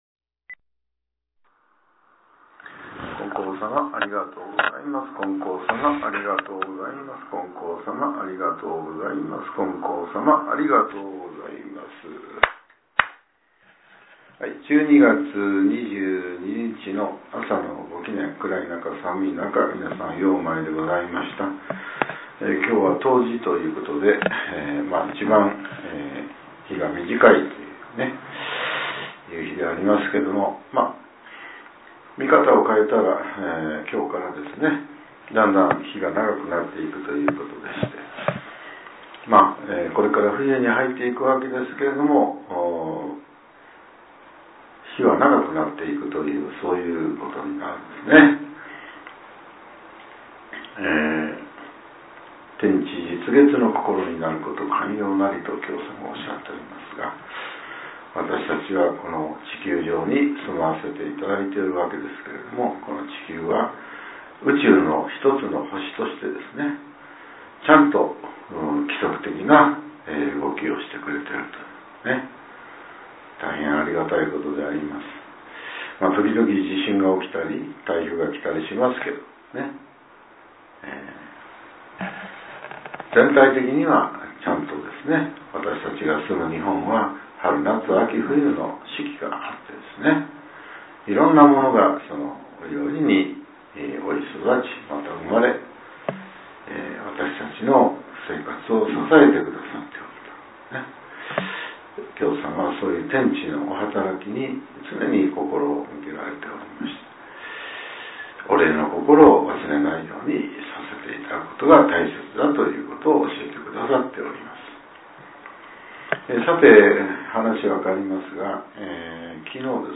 令和７年１２月２２日（朝）のお話が、音声ブログとして更新させれています。